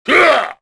Jin-Vox_Attack2_kr.wav